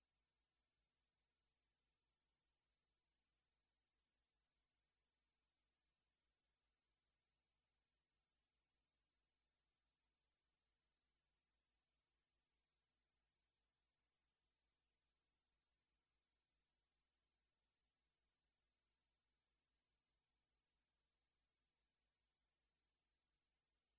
Full Length Version Musicals 3:38 Buy £1.50